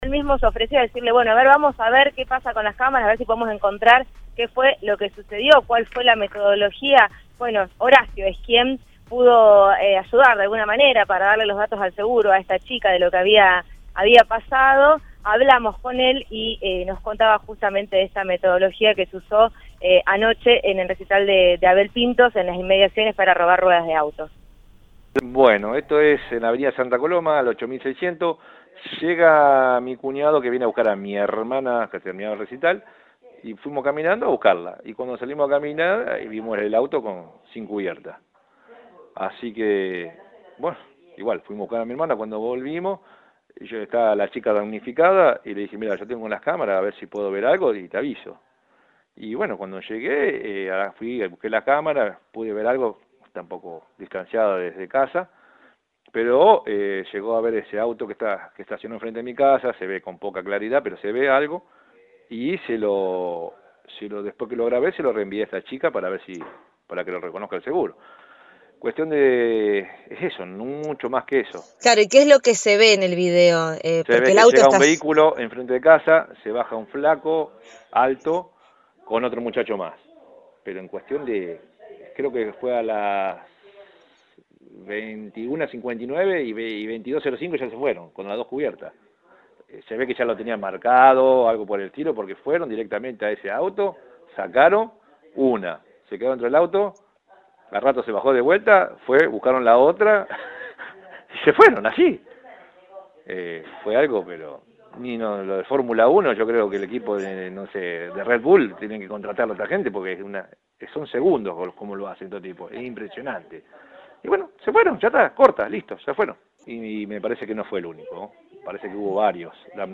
Un vecino captó la escena con cámaras y habló con Cadena 3 Rosario.